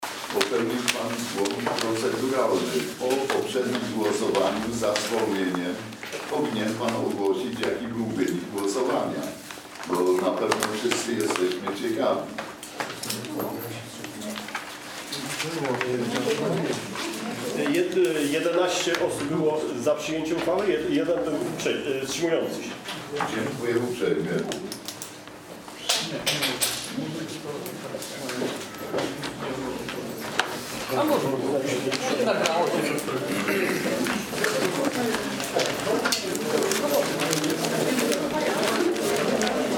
Nagranie: 47 sesja rady - 27 czerwca 2017 r. - część I